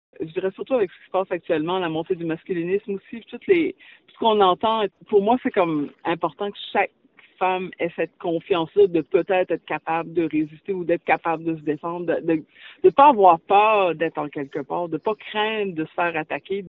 L’entraineuse